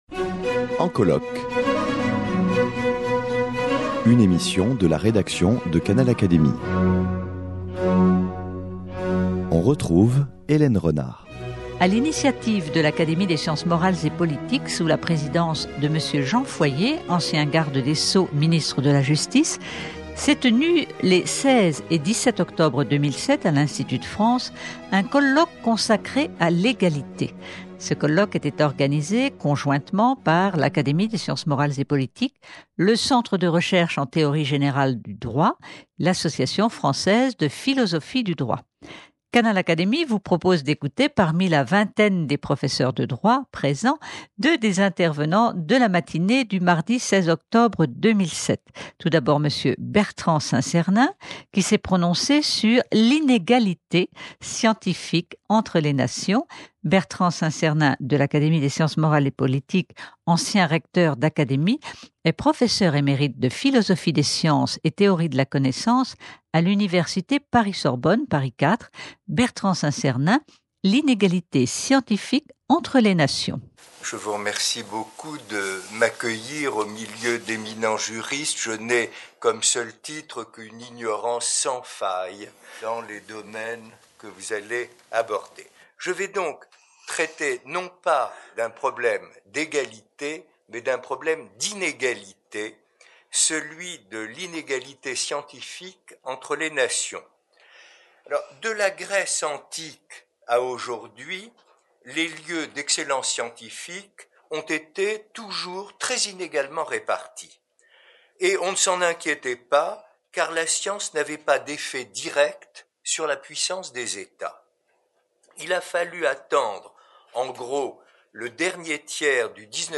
L’Egalité, tel était le thème du colloque organisé conjointement par l’Académie des sciences morales et politiques, le Centre de Recherches en Théorie général du Droit et l’Association française de Philosophie du Droit, qui s’est tenu, à l’Institut de France, les 16 et 17 octobre 2007. Une vingtaine d’intervenants ont pris la parole.